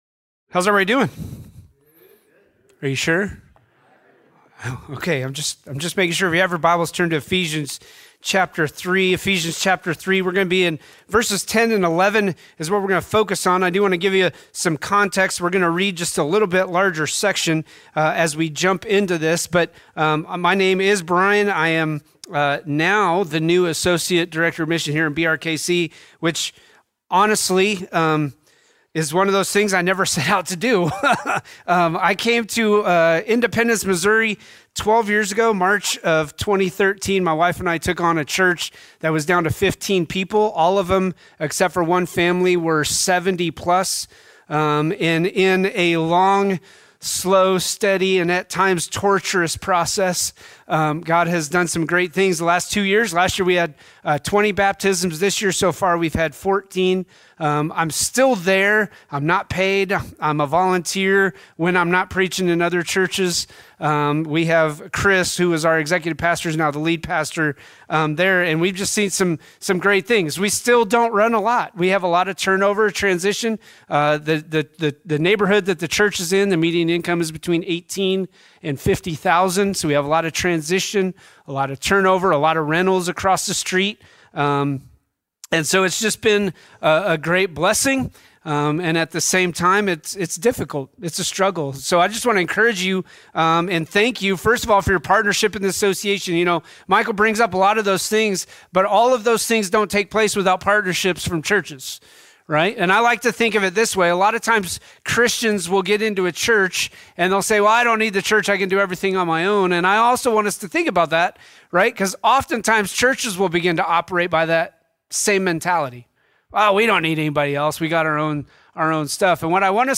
7-27-25-Sermon.mp3